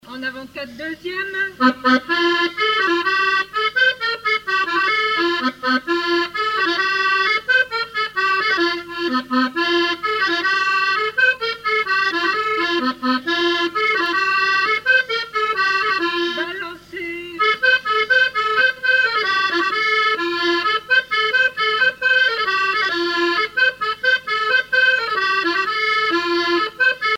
Mémoires et Patrimoines vivants - RaddO est une base de données d'archives iconographiques et sonores.
Musique du quadrille local
Pièce musicale inédite